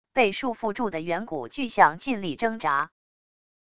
被束缚住的远古巨象尽力挣扎wav音频